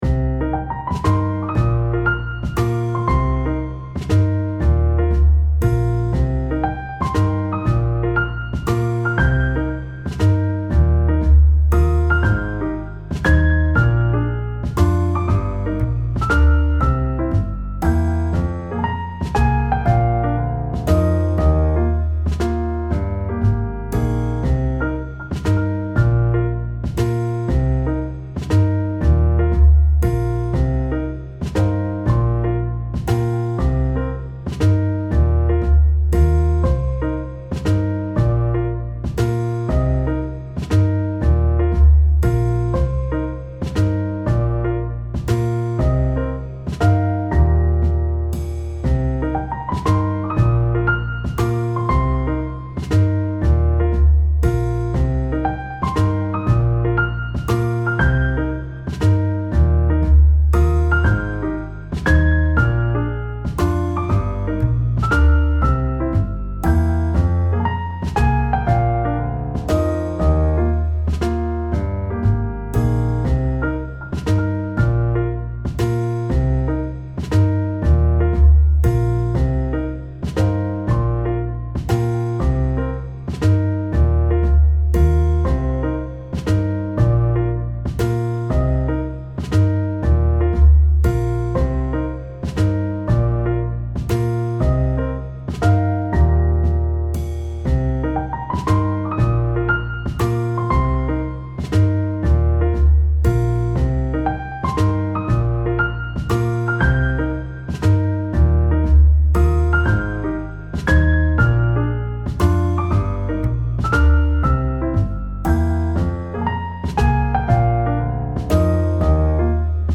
大人っぽい雰囲気は落ち着きを表現し、メロディには疲れを包み込む優しさを表現しました。
優しい おしゃれ 温かい 癒やし ゆったり 穏やか 心地よい
ジャズ